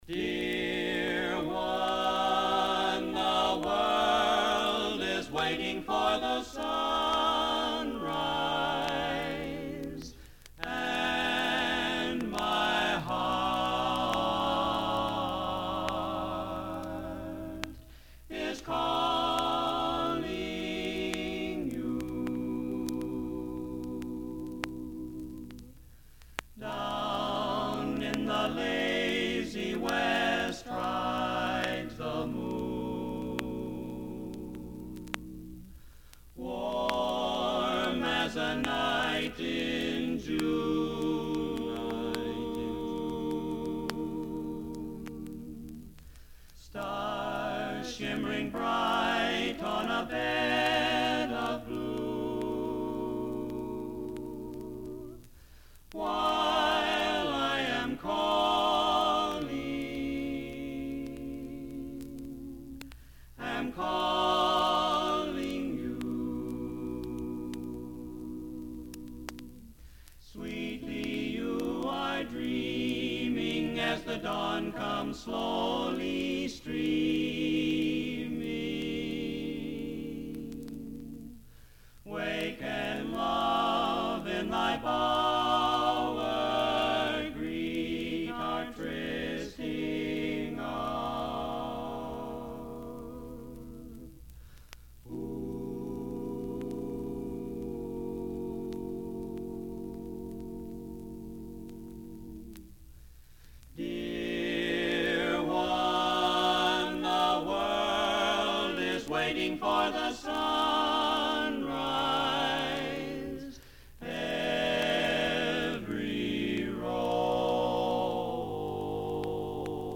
Tenor
Lead
Bari
Bass